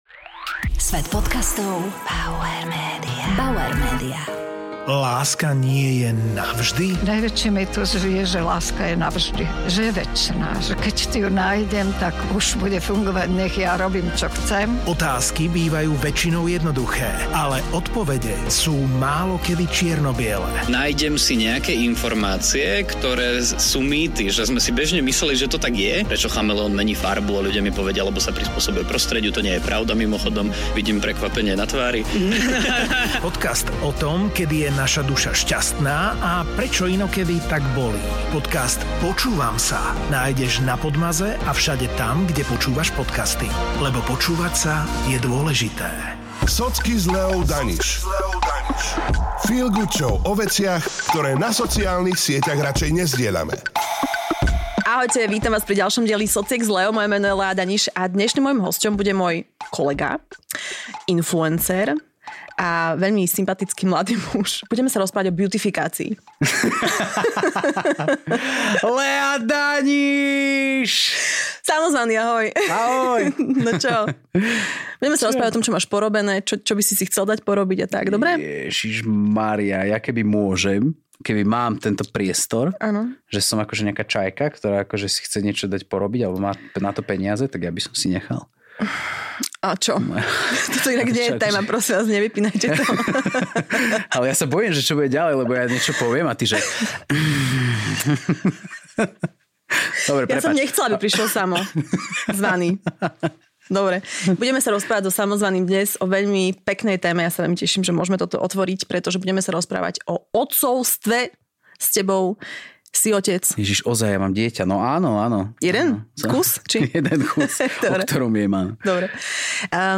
Vypočuj si silný a úprimný rozhovor o láske, únave, aj túžbe nebyť len „dobrým otcom“, ale najmä dobrým mužom.